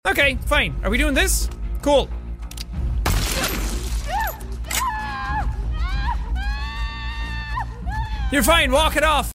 Also, the screaming actually kind of sounds like Celestia 😭